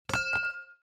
SFX_Glass_Table.mp3